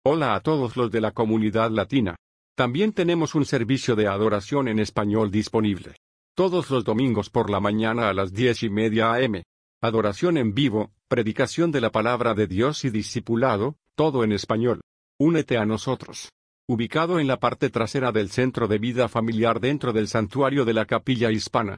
10:30AM Mensaje de invitación al Servicio de Adoración Dominical